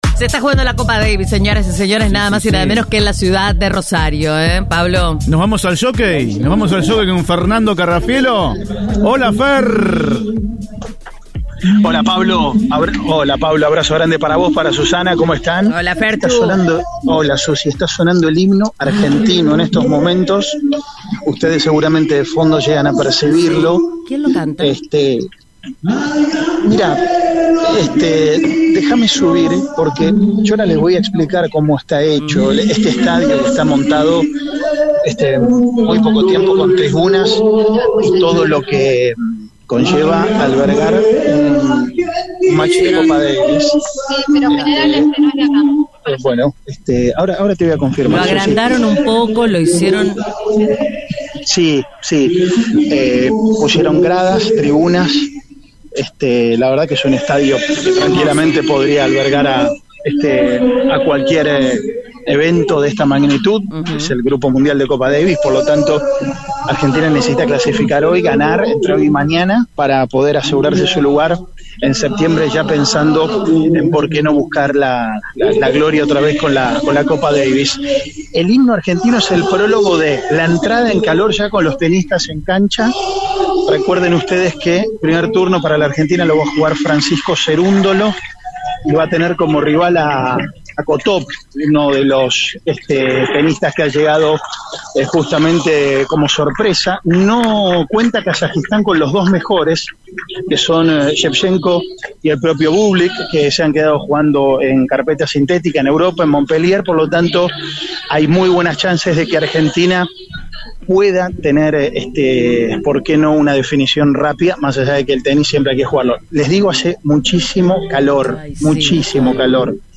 En diálogo con Cadena 3 Rosario, desde el Jockey Club, Diego Sebben, subsecretario de Deportes municipal, afirmó que “esta ciudad tiene mucha historia deportiva” y destacó como hito fundacional, en 2010, el Mundial de Hockey que consagró a Las Leonas.
Informe